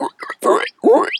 pgs/Assets/Audio/Animal_Impersonations/pig_2_hog_seq_10.wav at master
pig_2_hog_seq_10.wav